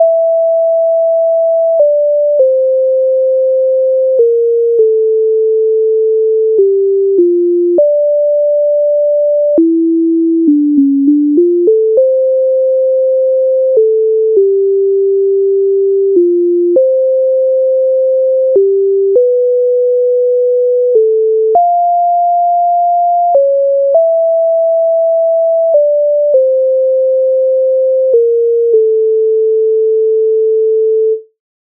MIDI файл завантажено в тональності d-moll